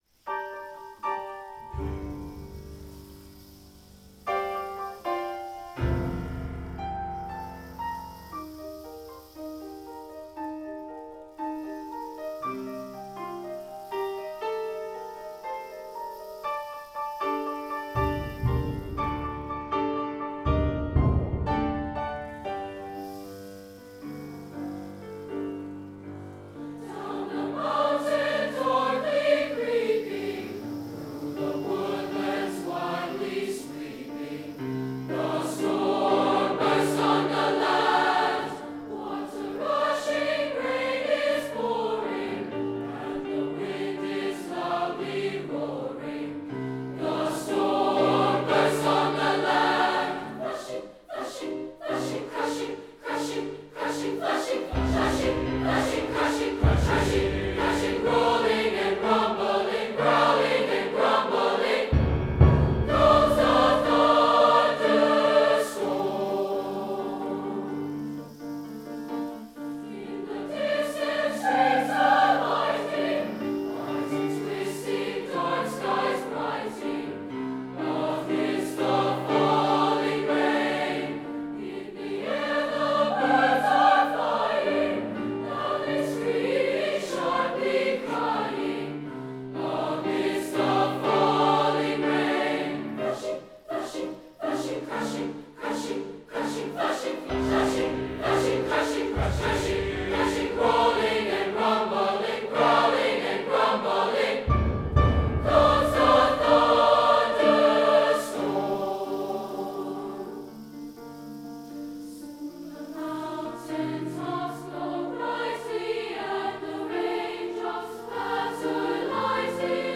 Voicing: S(S)ATB